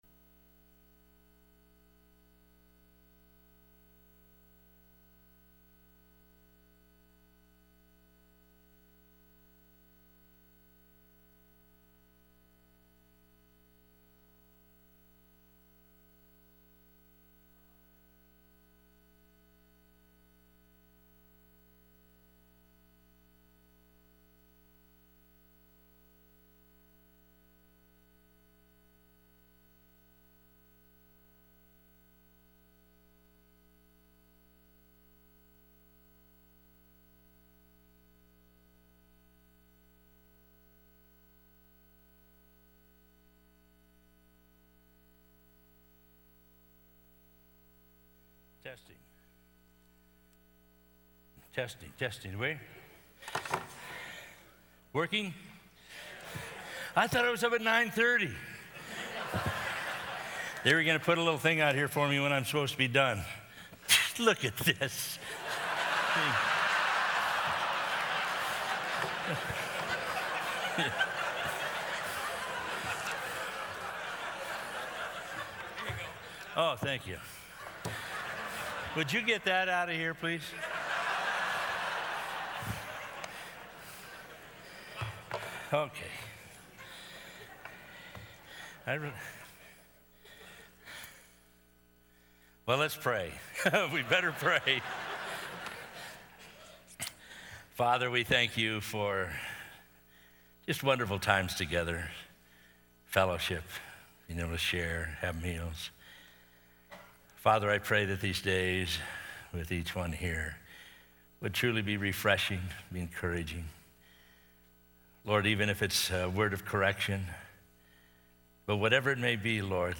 Southwest Pastors and Leaders Conference 2013